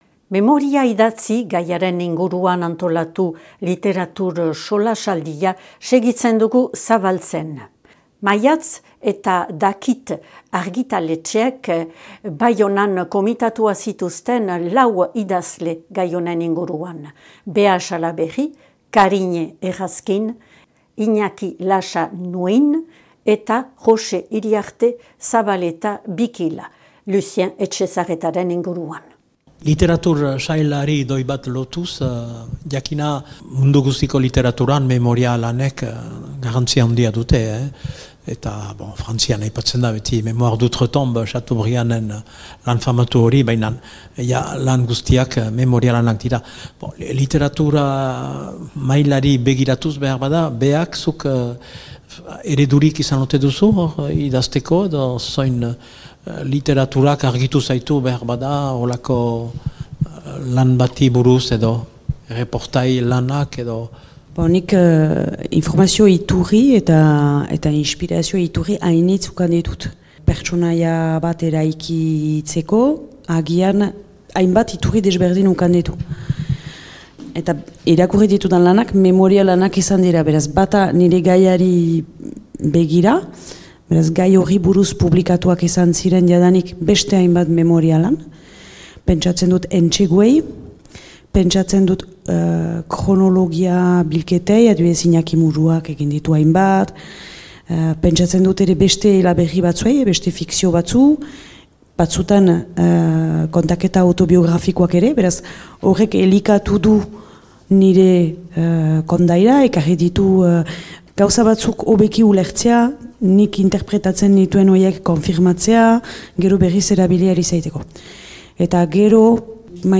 Literatur solasaldia : memoria idatzi 2. partea)